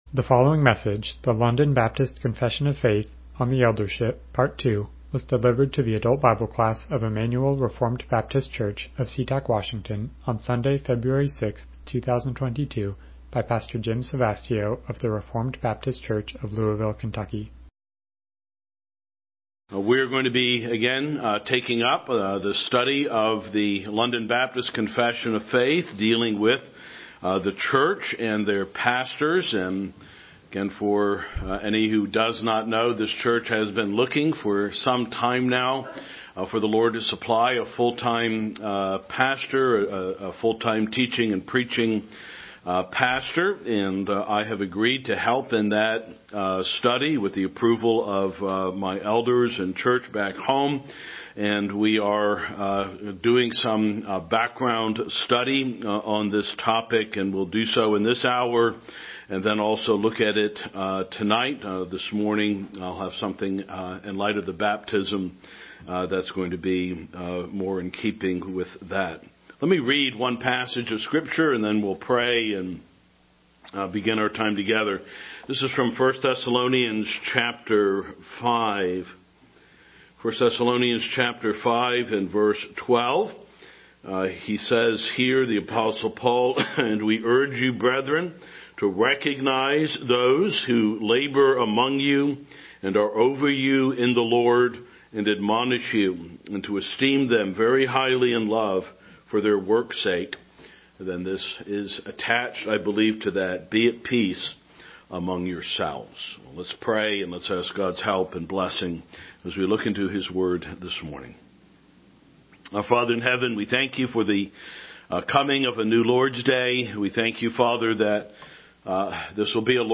Service Type: Sunday School Topics: Confession of Faith « Shepherds After God’s Own Heart